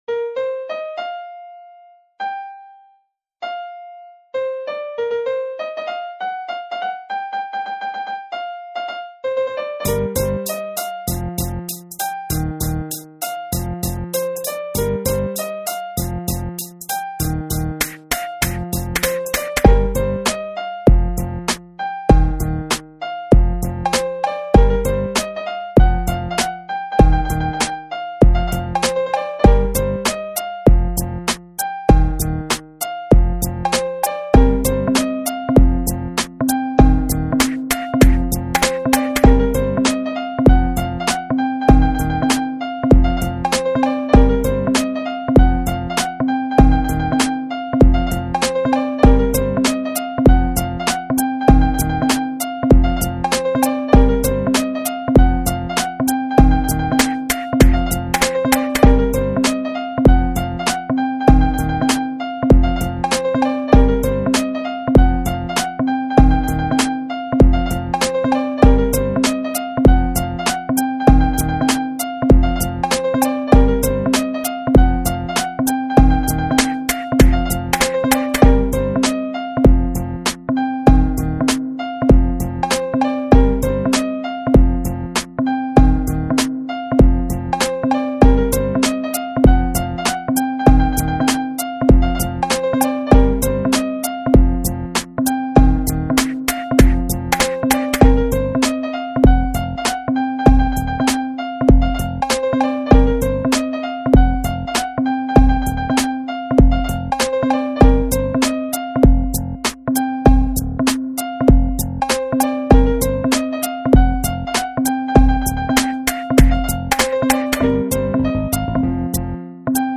inst hiphop ベースラインとピアノを２バース に分けたものを適当に割り振りしてメロディを制作。
ピアノのエフェクトがもっと綺麗にかかっていればマシになったかも。